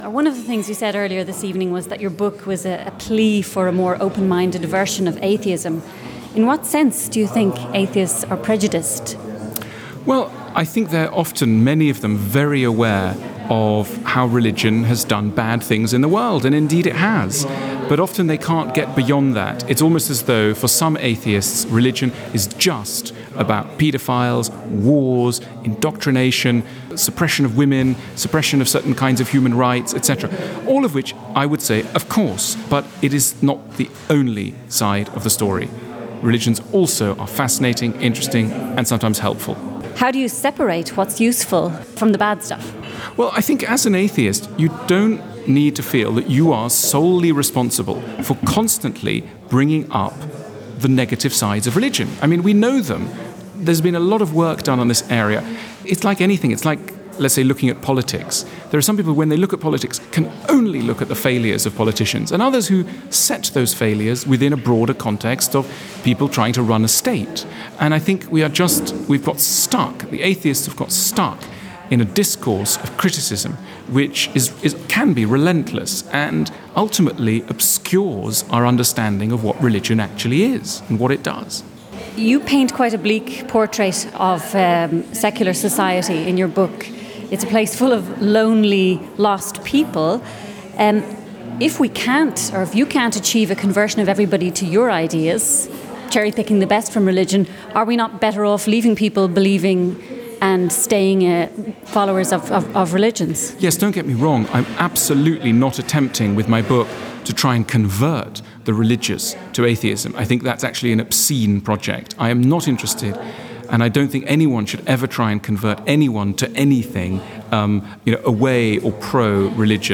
Alain de Botton speaking
in Basel after giving a talk about Religion for Atheists